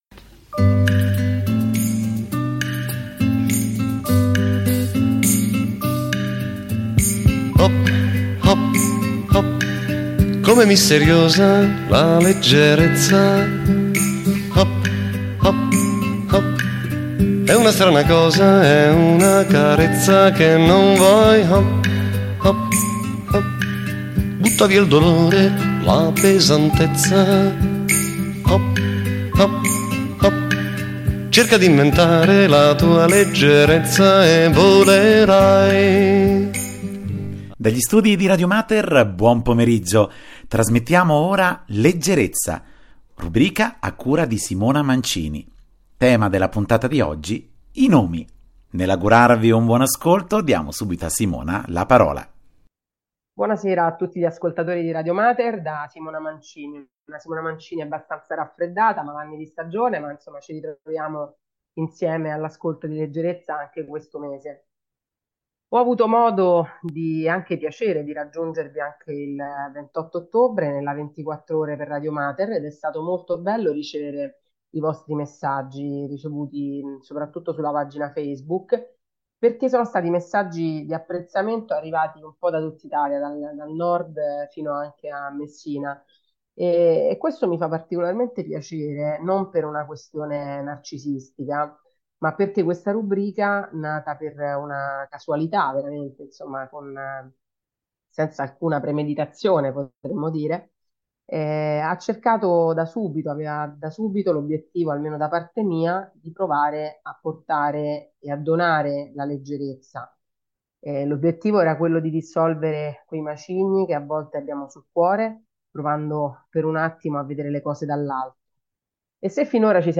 Anche nella musica esistono nomi diventano titoli di brani musicali e canzoni.